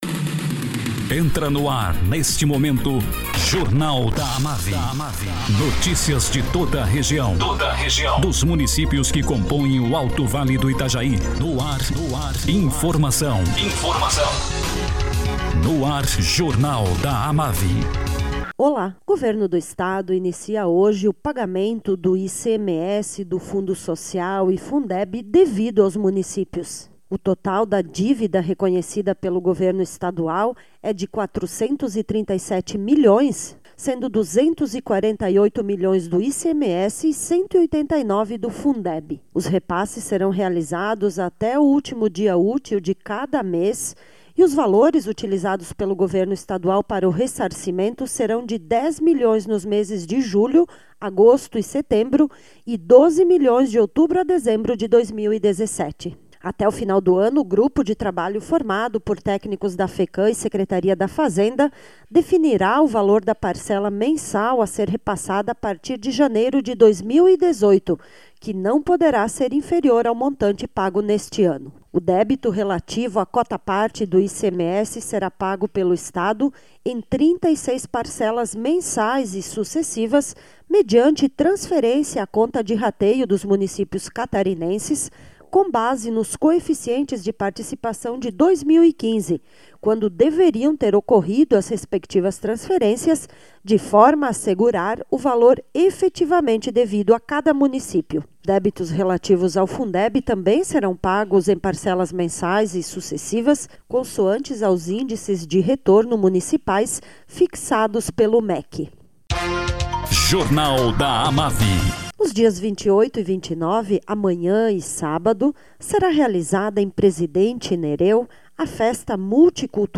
Prefeito de Presidente Nereu, Isamar de Melo, fala sobre a programação da Festa Multicultural e do Colono que será realizada amanhã e sábado, 28 e 29 de julho no Centro Social João Voltolini.